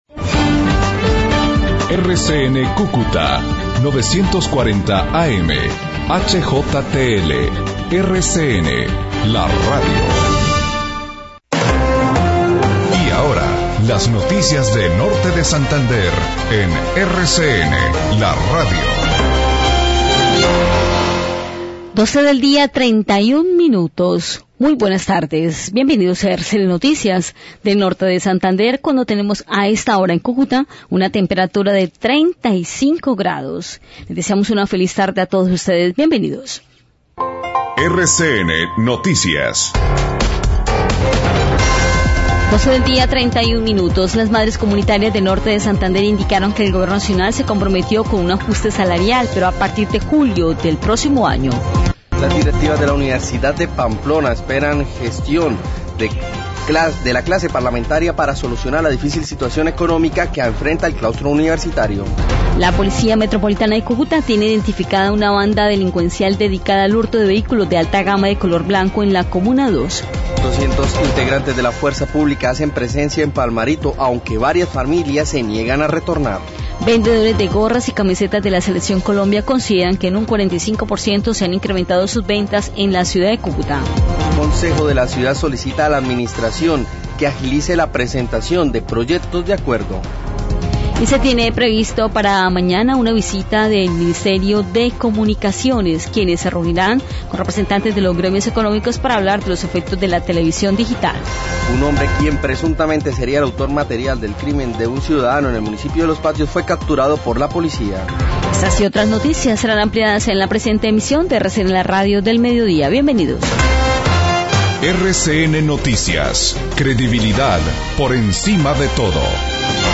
RCN 940AM Cúcuta, noticias de la mañana → Periodistas de RCN Noticias desde RCN Radio Cúcuta 940AM.